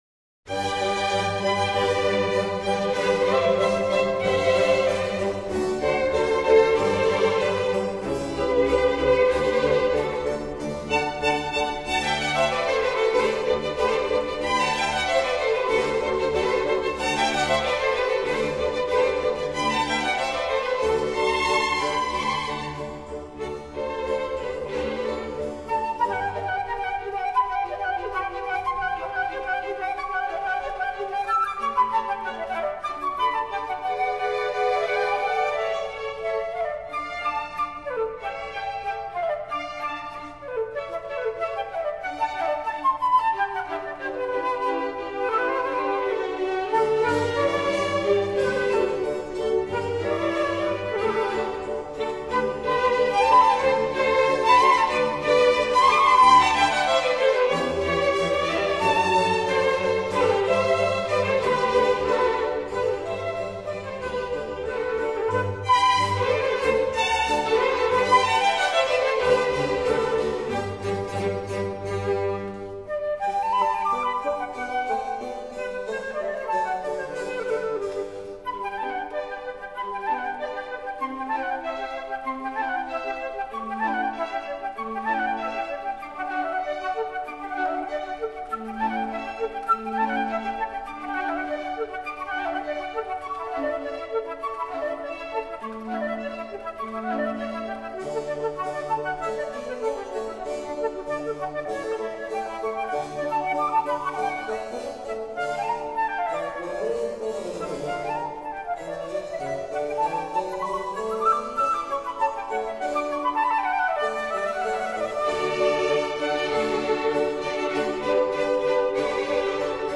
Concerto for Flute & Orchestra in G major: I. Allegro